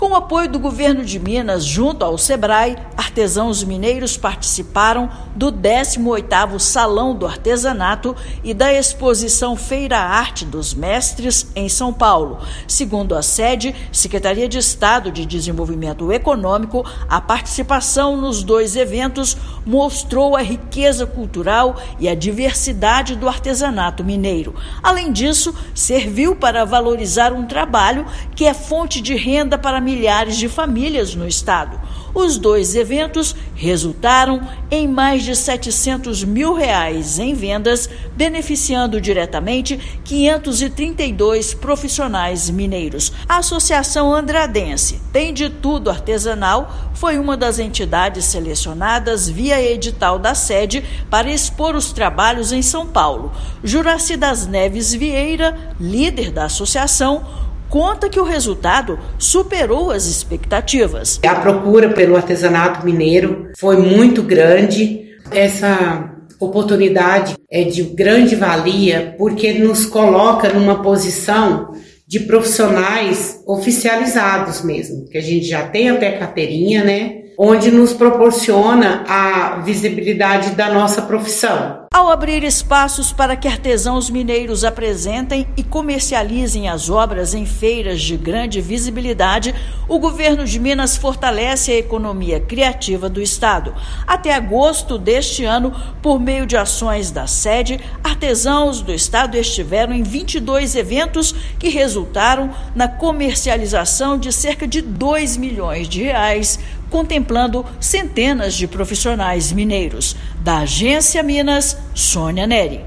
[RÁDIO] Governo de Minas apoia artesanato mineiro em eventos nacionais e impulsiona geração de renda
Com suporte da Sede-MG, comercialização de produtos já supera R$ 2 milhões por ano. Ouça matéria de rádio.